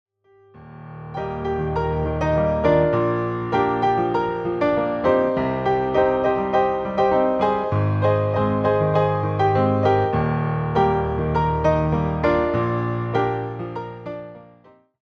reimagined as solo piano arrangements.